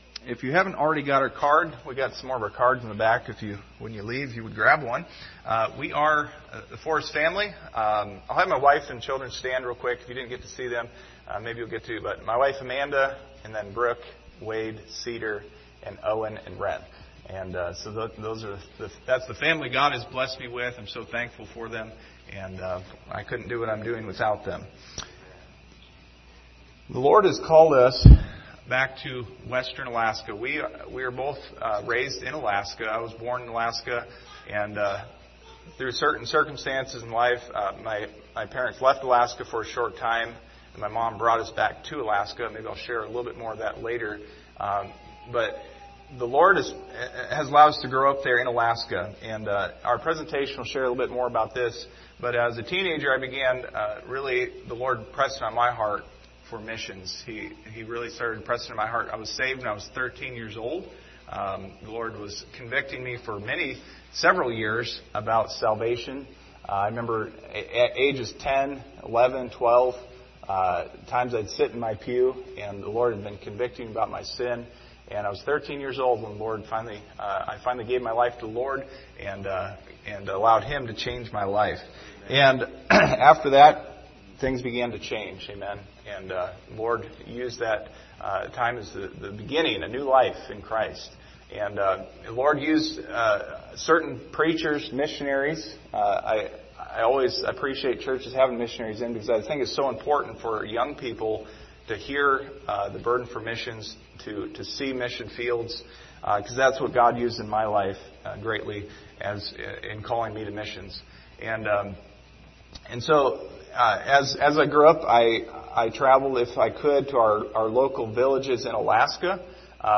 Passage: James 1:27 Service Type: Wednesday Evening Topics